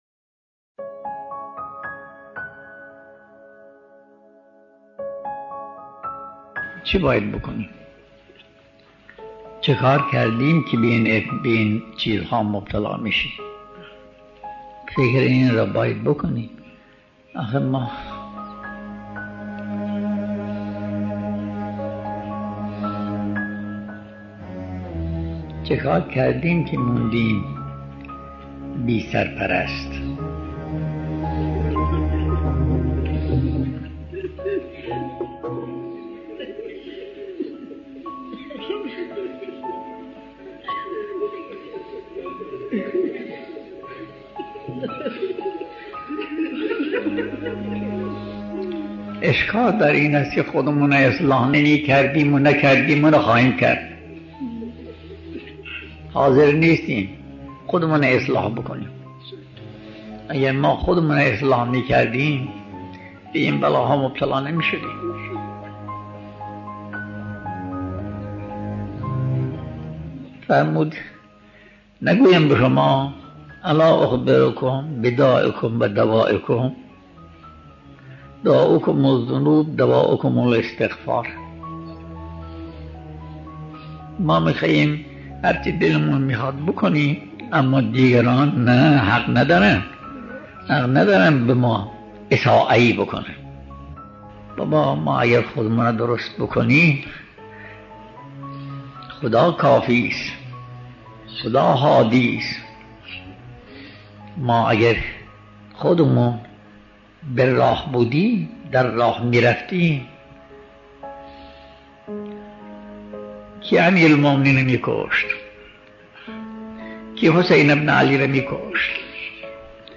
سخنرانی
ایت الله بهجت ره - سخنرانی اقا بهجت ره